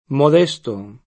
modesto [mod$Sto] agg. — sim. il pers. m. Modesto [mod$Sto; sp.